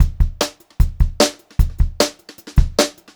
152H2BEAT4-R.wav